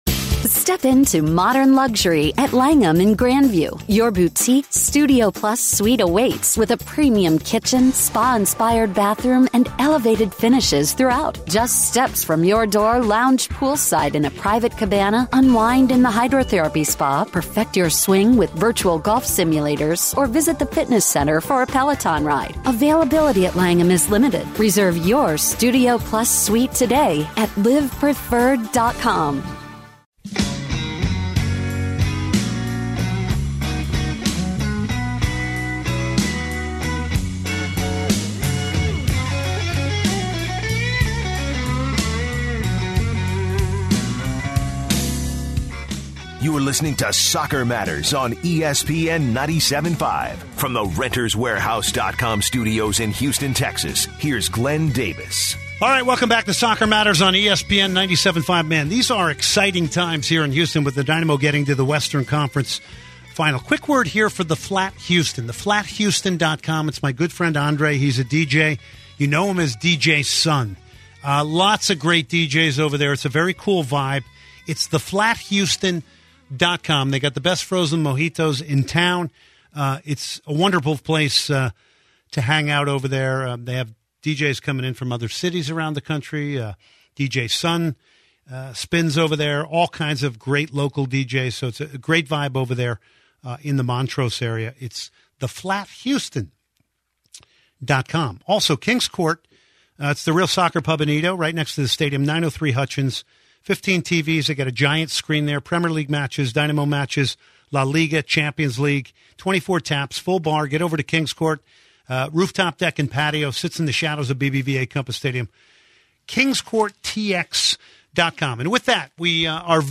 Philippe Senderos - Full Interview